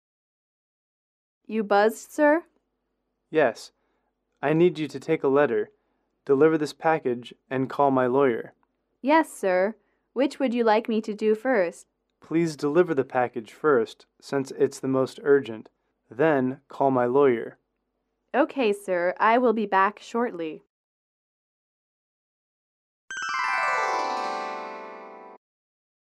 英语主题情景短对话42-3：吩咐工作(MP3)